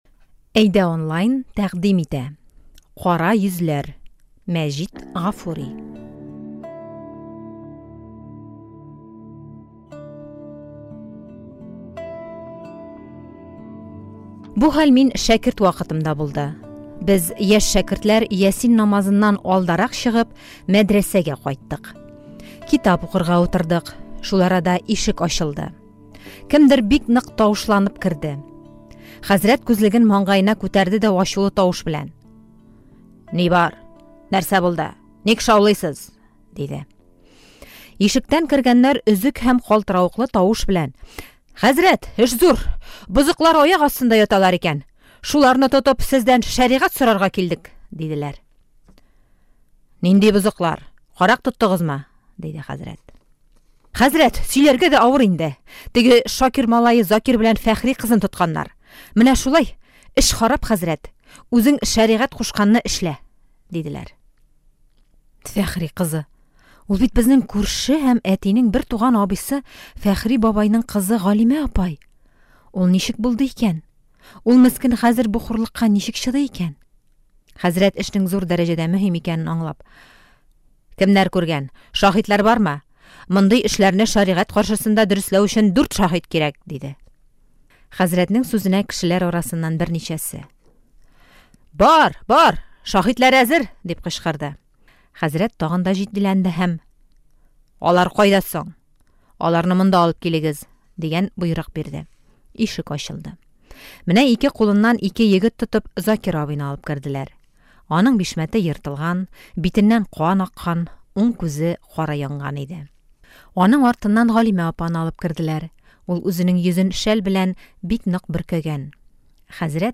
Читаем татарскую классику: одну из самых известных повестей Мажита Гафури "Кара йөзләр".